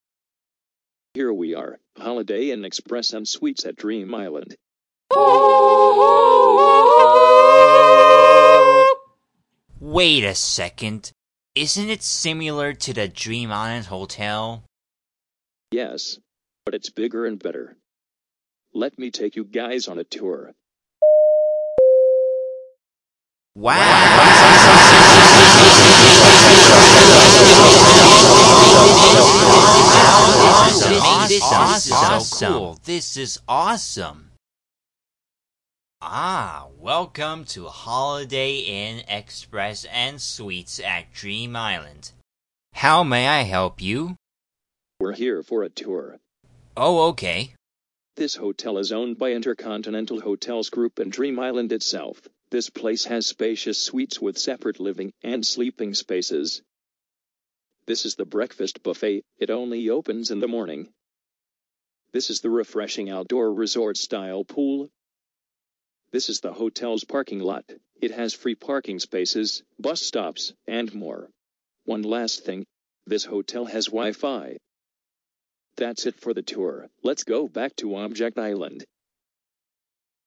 这是来自BFDI电影的Holiday Inn Express场景的对话。